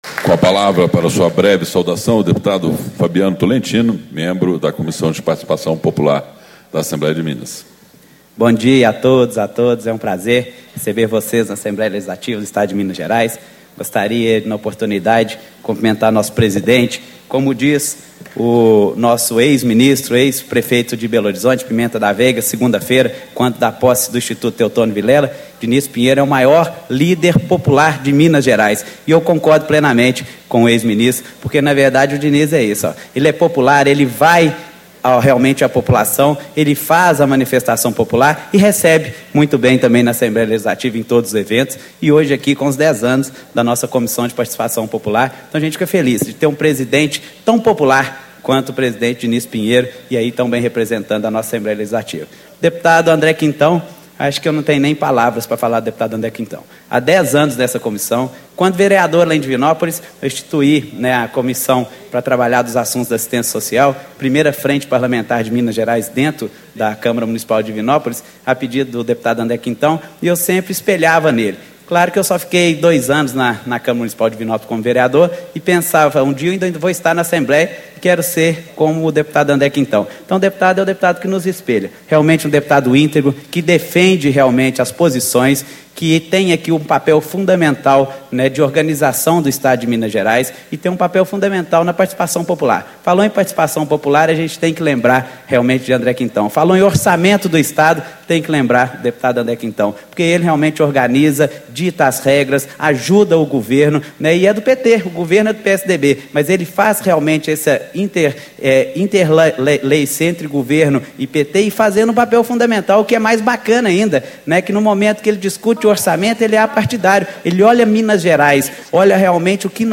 Abertura - Deputado Fabiano Tolentino, PSD - Integrante da Comissão de Participação Popular
Discursos e Palestras